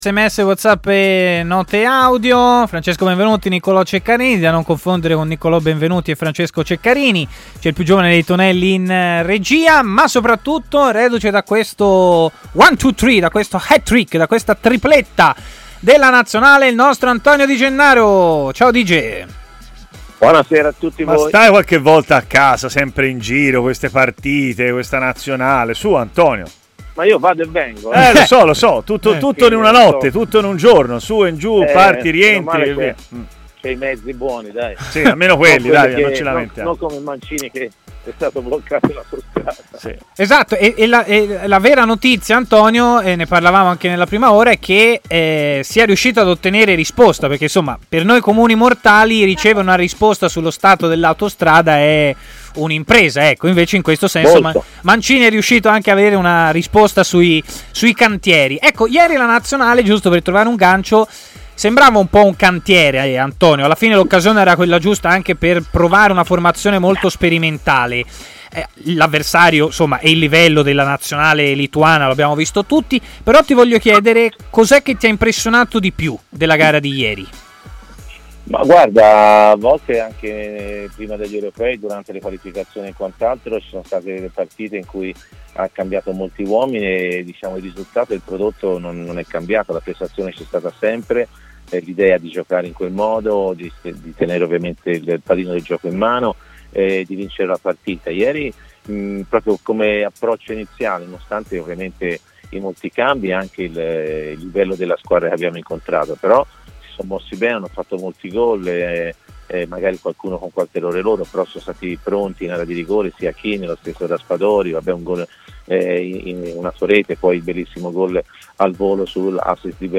L'ex centrocampista Antonio Di Gennaro ha così parlato a Stadio Aperto, trasmissione di TMW Radio